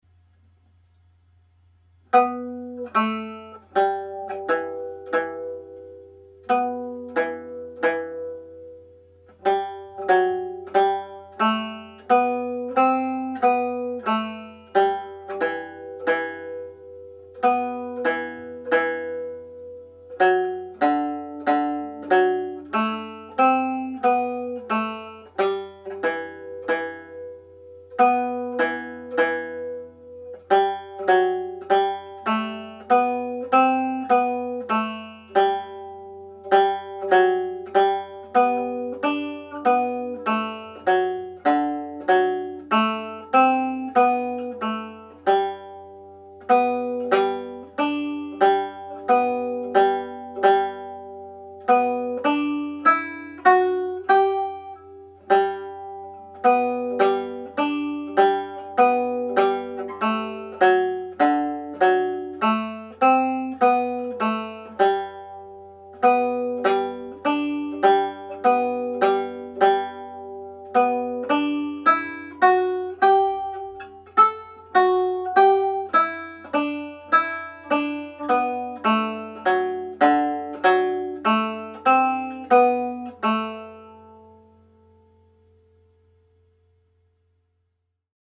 Reel (E Minor)
played at slow speed
Fermoy-Lasses-slow-speed.mp3